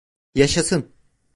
Pronunciado como (IPA) [jɑʃɑˈsɯn]